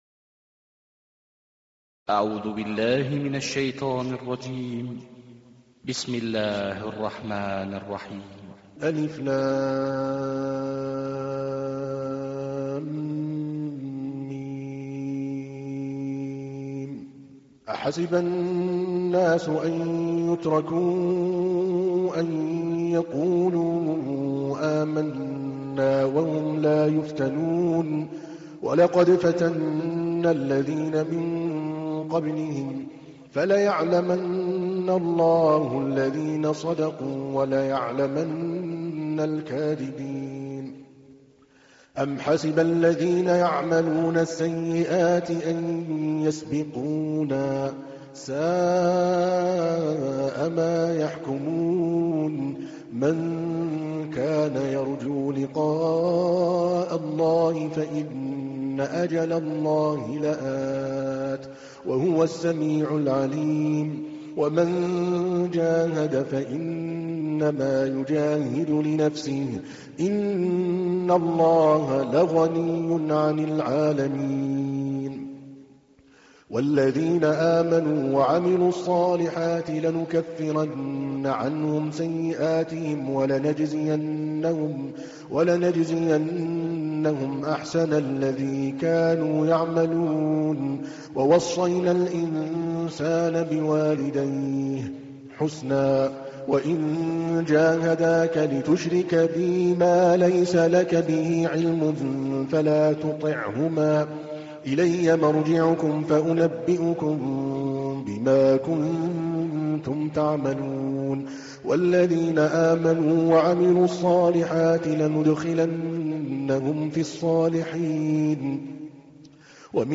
تحميل : 29. سورة العنكبوت / القارئ عادل الكلباني / القرآن الكريم / موقع يا حسين